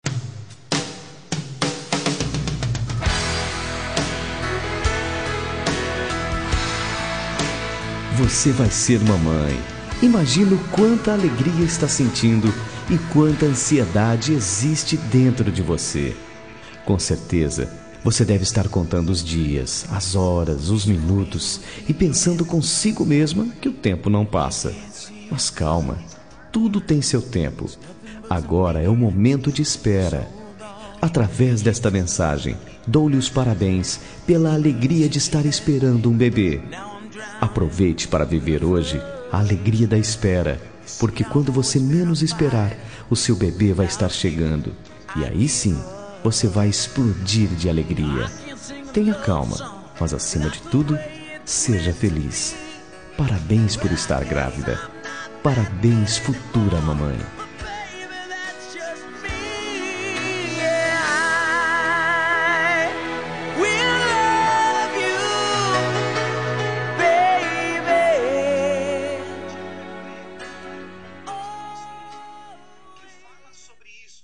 Telemensagem para Gestante – Voz Masculina – Cód: 6633